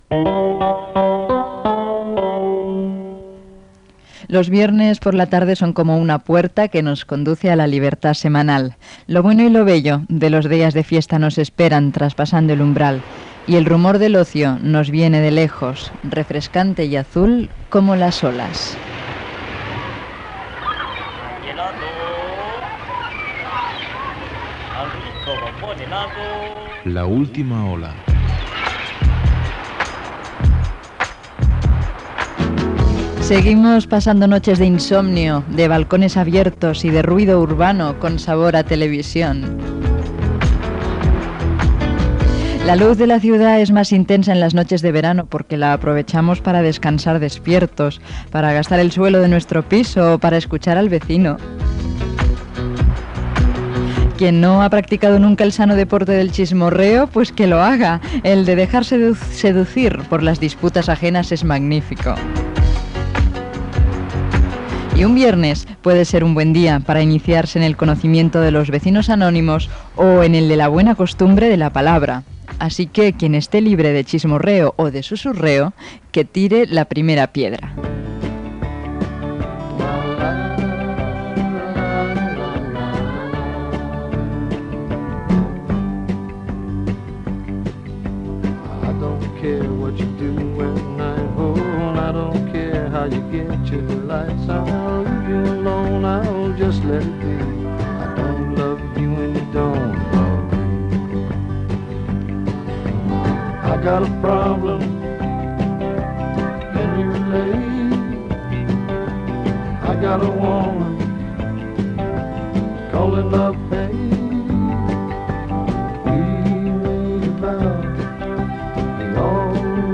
Sintonia de la cadena, reflexió sobre els divendres, indicatiu del programa, comentari sobre les nits d'estiu, tema musical, publicitat
Gènere radiofònic Entreteniment